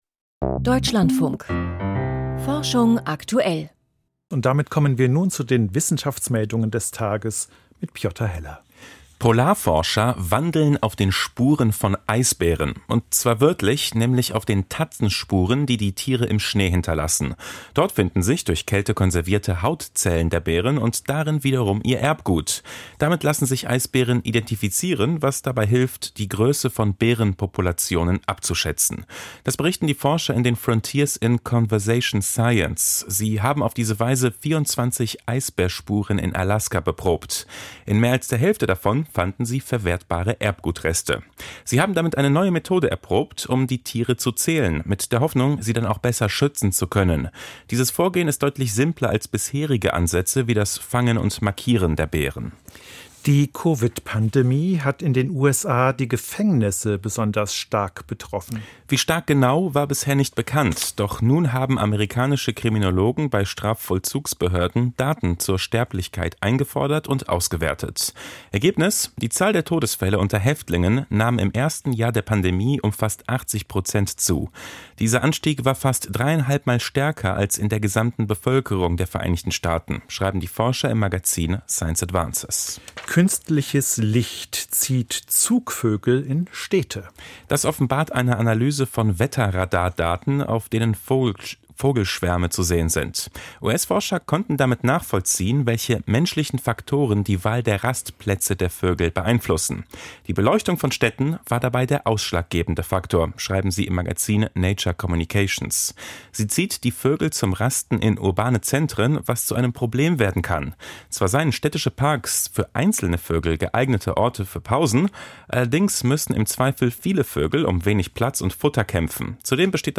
Wissenschaftsmeldungen 07.03.2025. Immer mehr invasive Raubfische im Panamakanal: Interview